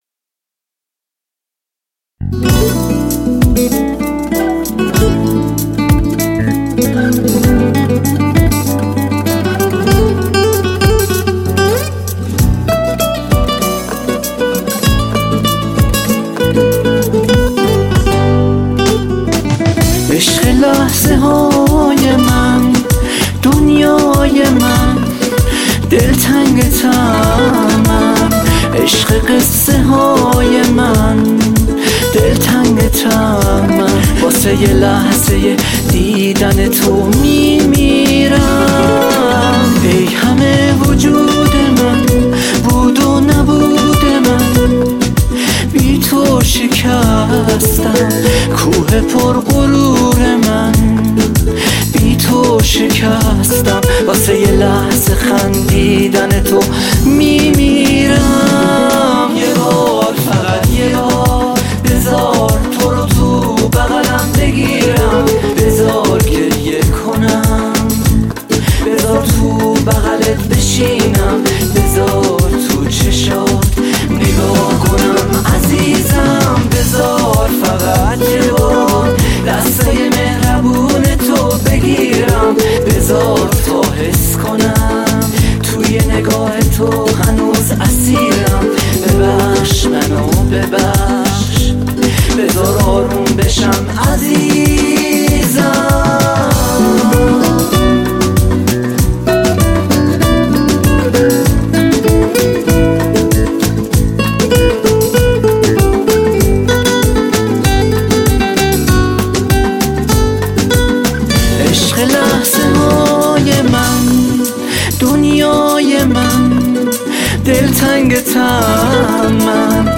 با احساس میخونه